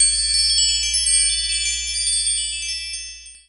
sand1.wav